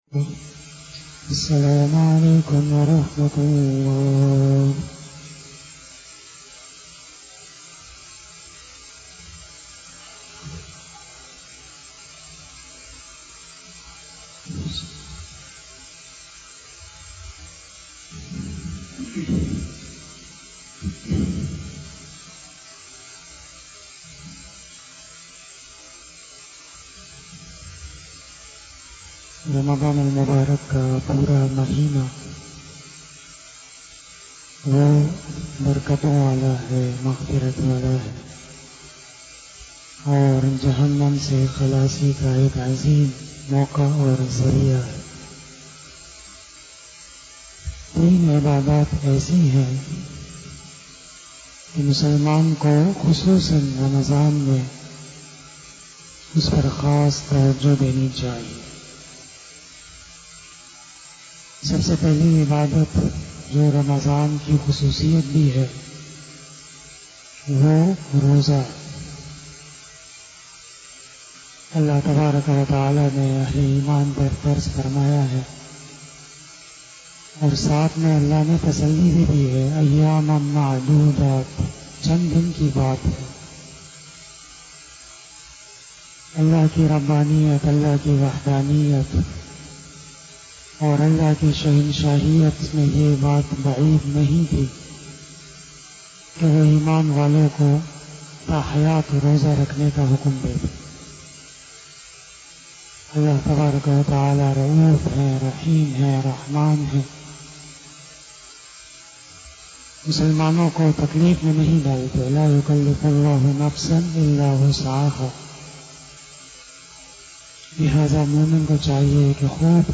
007 After Asar Namaz Bayan 14 April 2021 ( 01 Ramadan 1442HJ) Wednesday